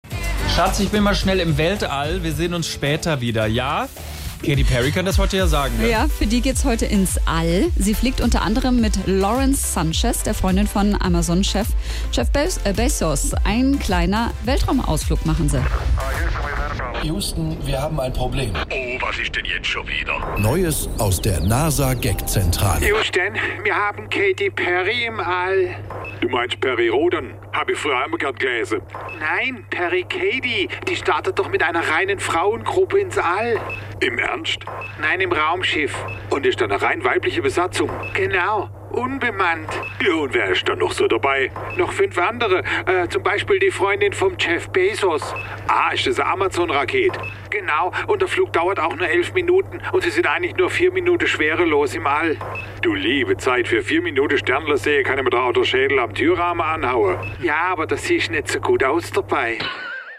Die SWR3 Comedy hat sich überlegt, ob es nicht günstige Alternativen zum Weltraumflug gibt: